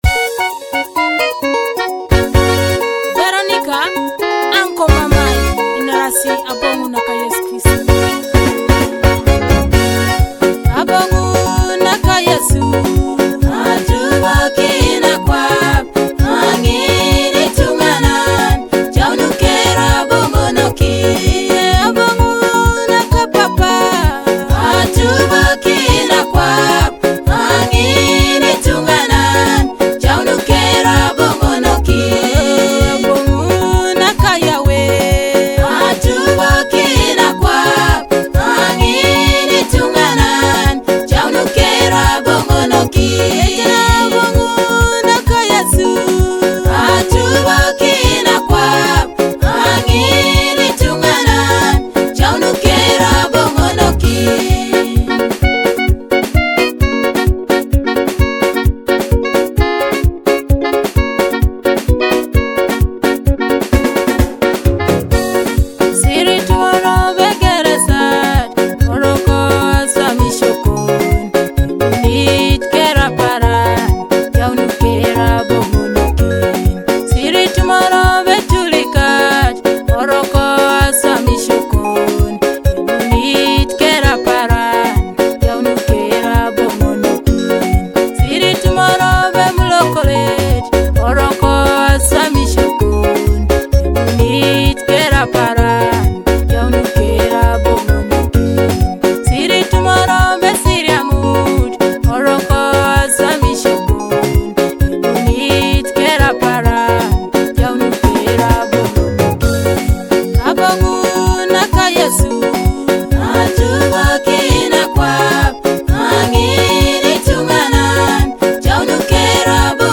Teso gospel
powerful gospel song
Through soulful vocals and an uplifting melody